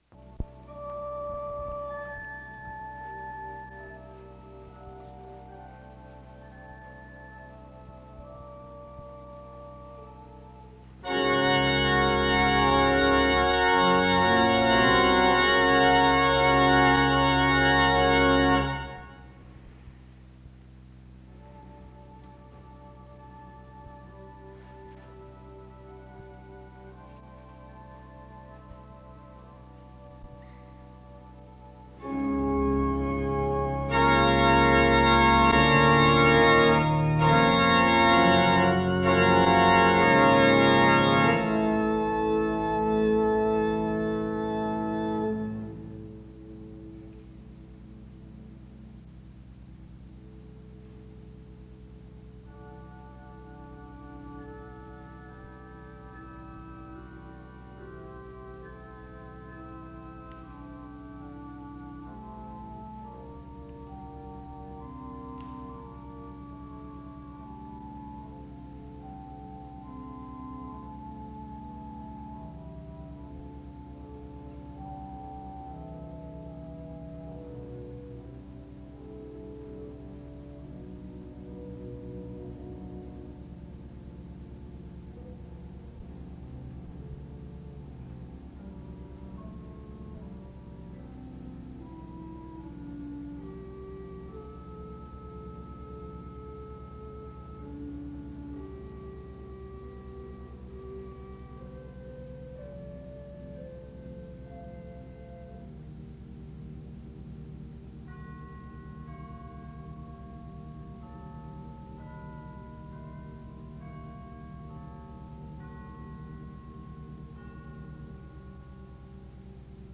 Download .mp3 Description The service opens with music from 0:00-2:11.
Music plays from 3:19-6:43. Romans 12 is read from 7:02-9:43. A prayer is offered from 9:54-11:46.
Music plays from 18:08-22:26. The outgoing and incoming council members are recognized from 22:33-30:50.